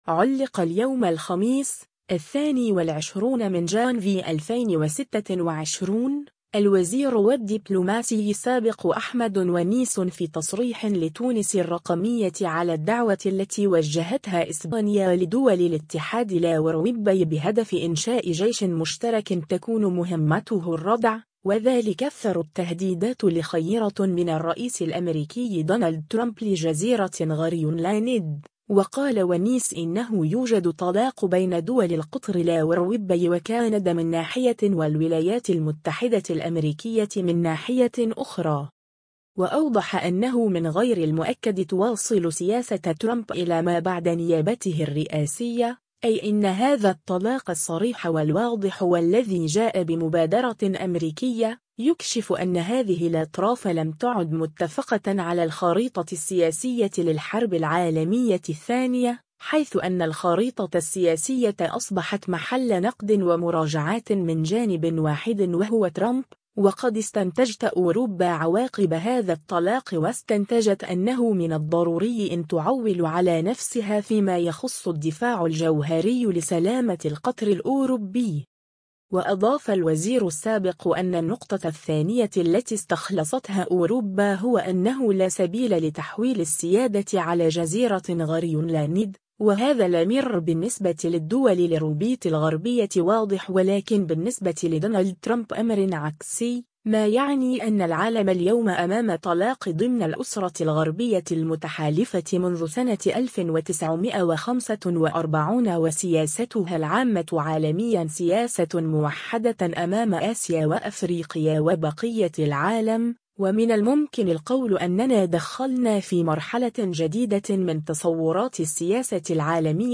علّق اليوم الخميس، 22 جانفي 2026، الوزير و الدّبلوماسي السابق أحمد ونيس في تصريح لتونس الرّقمية على الدّعوة التي وجّهتها إسبانيا لدول الاتحاد الاوروبي بهدف انشاء جيش مشترك تكون مهمّته الرّدع، و ذلك اثر التّهديدات الاخيرة من الرّئيس الأمريكي دونالد ترامب لجزيرة غرينلاند، و قال ونيس إنّه يوجد طلاق بين دول القطر الاوروبي و كندا من ناحية و الولايات المتحدة الأمريكية من ناحية أخرى.